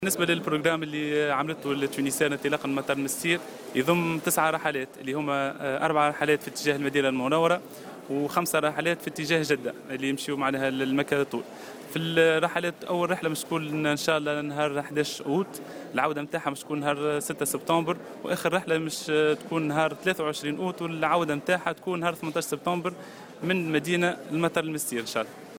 وأضاف في تصريح لـ"الجوهرة أف أم" أن أولى الرحلات ستكون يوم 11 أوت المقبل على أن تكون العودة يوم 6 سبتمبر وستكون آخر رحلة يوم 23 أوت والعودة يوم 18 سبتمبر.